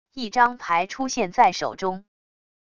一张牌出现在手中wav音频